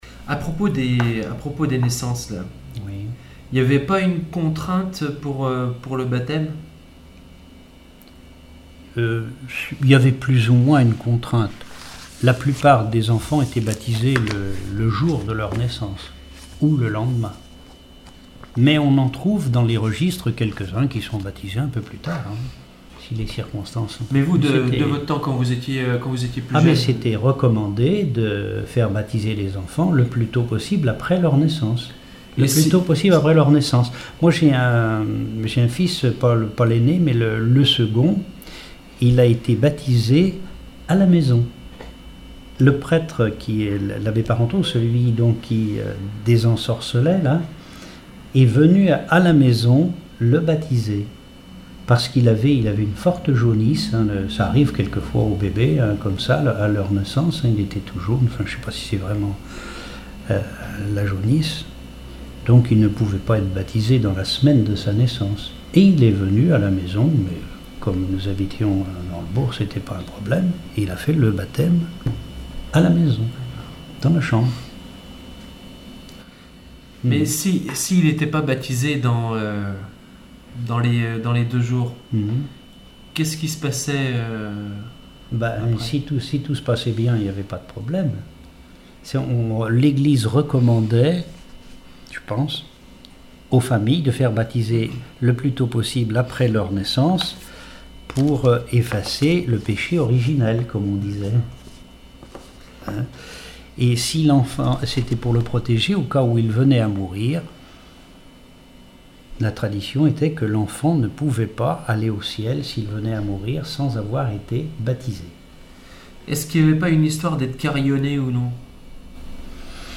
Témoignages ethnologiques et historiques
Catégorie Témoignage